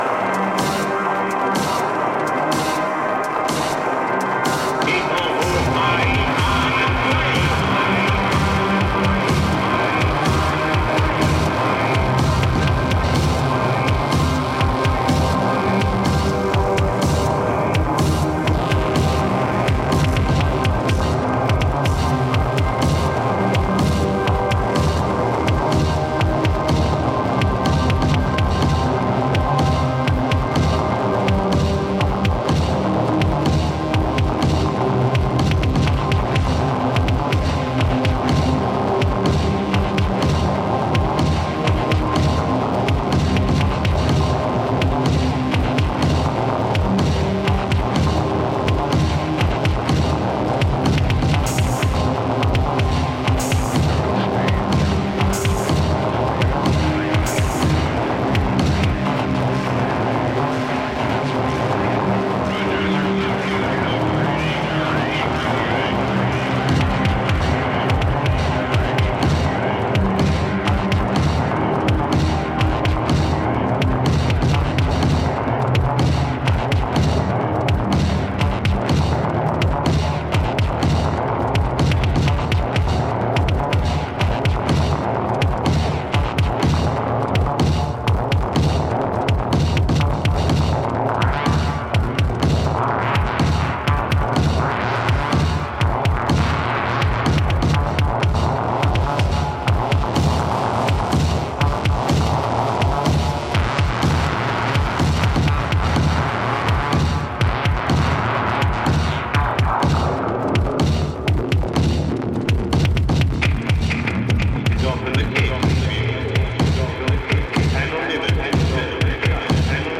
EBM/Industrial
A3. From the north, this cut comes with epic reminiscences..